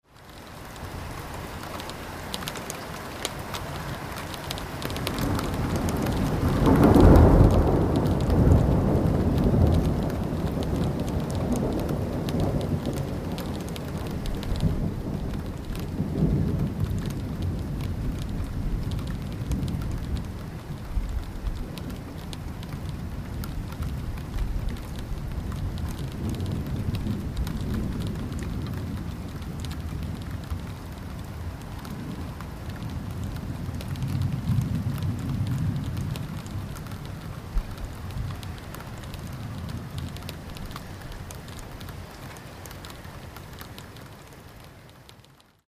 Moderately close thunder clap